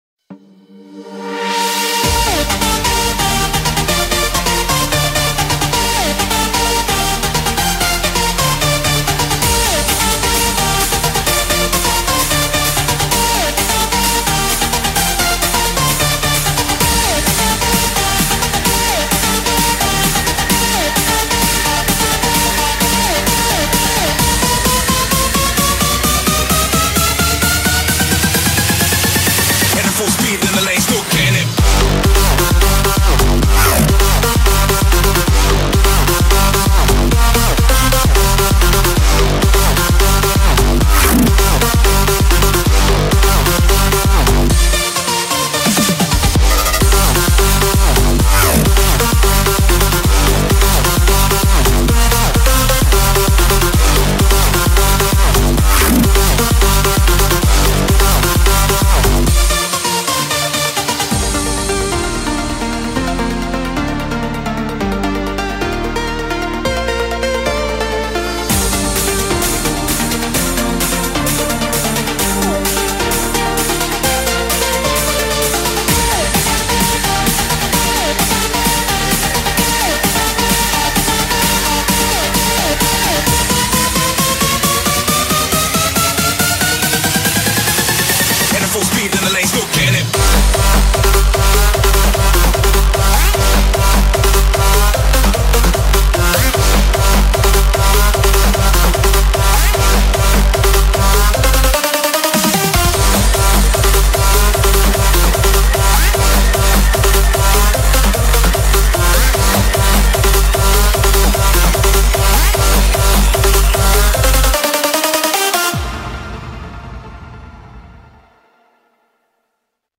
BPM130-150
Audio QualityPerfect (Low Quality)